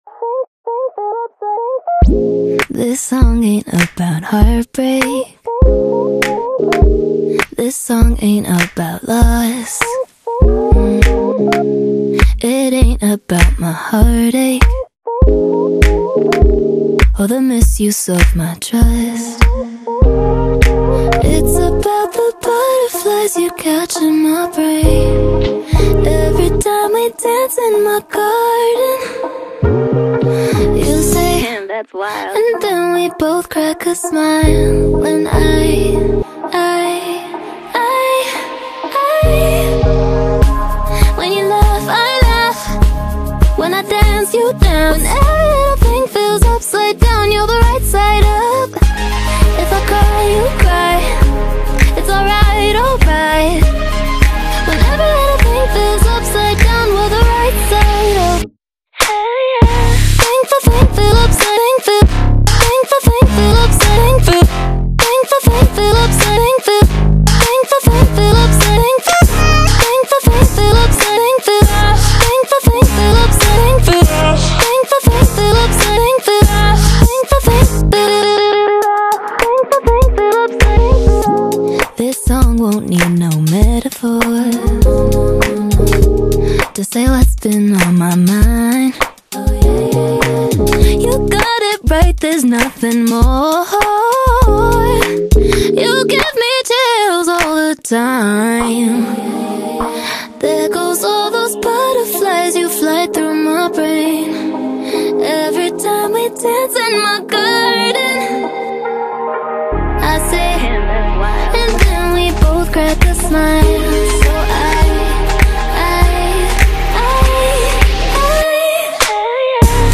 BPM: 100